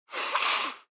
Звуки сморкания
Короткий звук сморчка